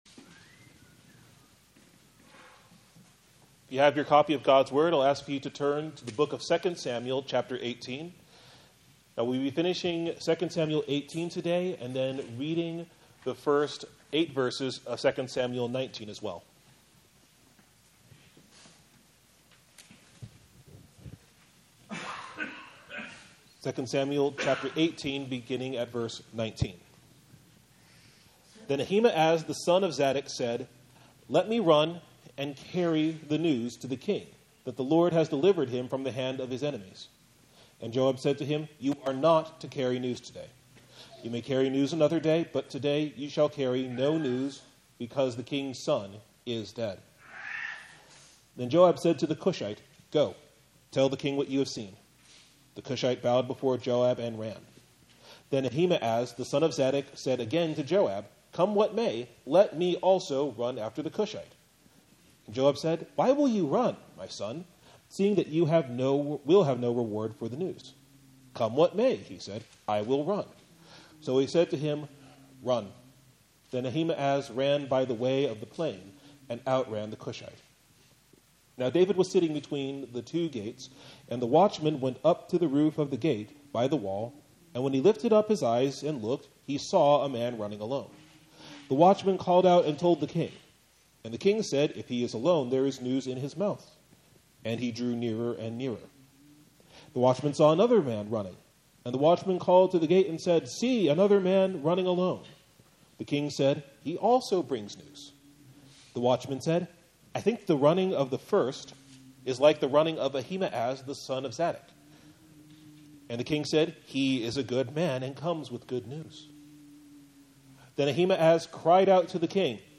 Sermon Text: 2 Samuel 18:19-19:8a